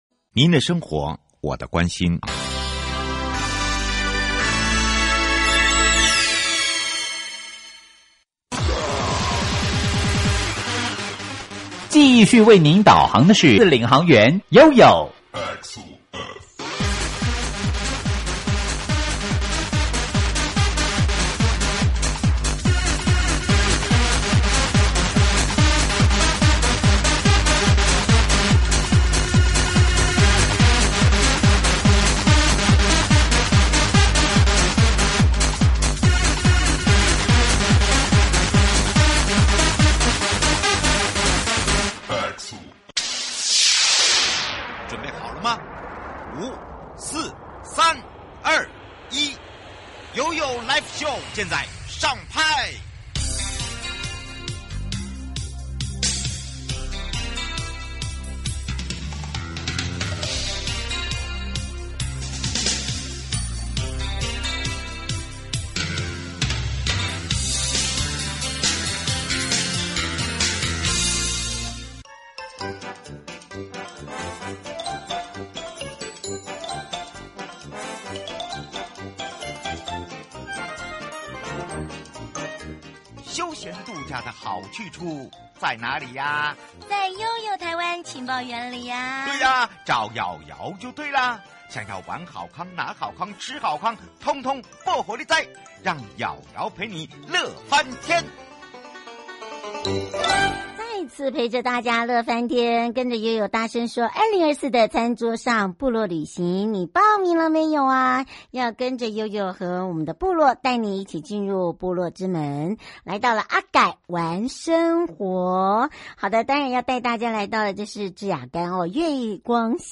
受訪者： 1.花東縱谷管理處阿改玩生活(支亞干部落